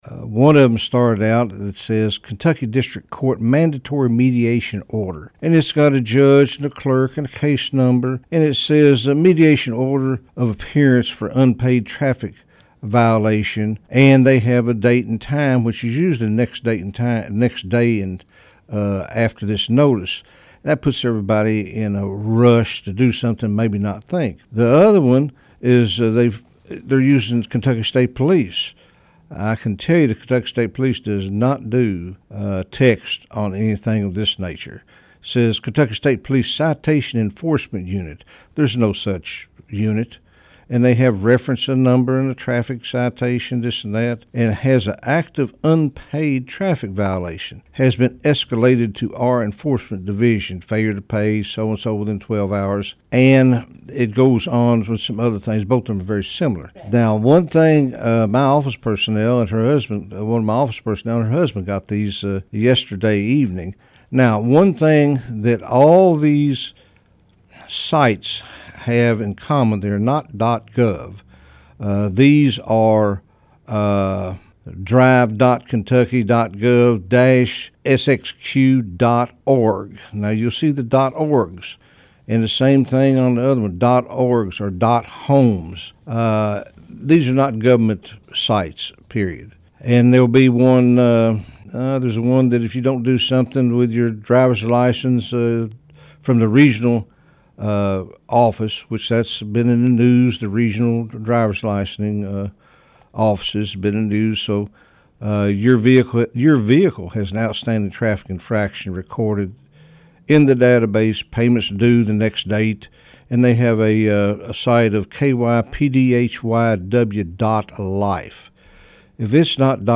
Listen to Sheriff Weedman’s visit below.